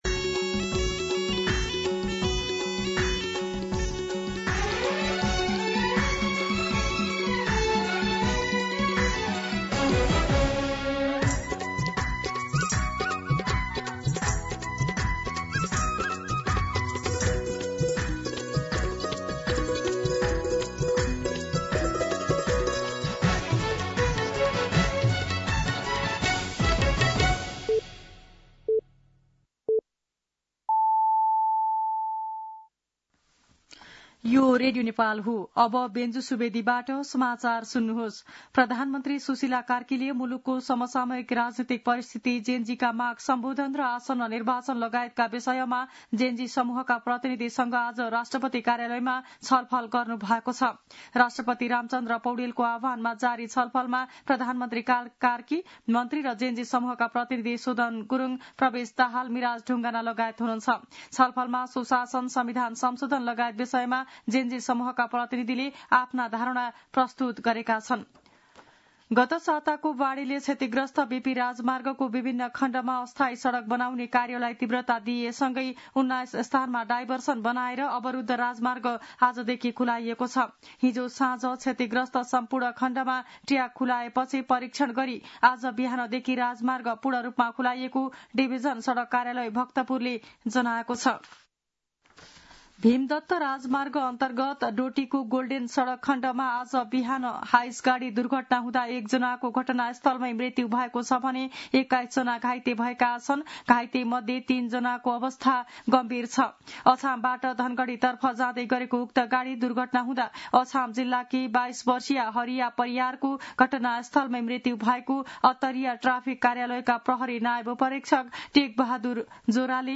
दिउँसो १ बजेको नेपाली समाचार : २५ असोज , २०८२
1-pm-Nepali-News-4.mp3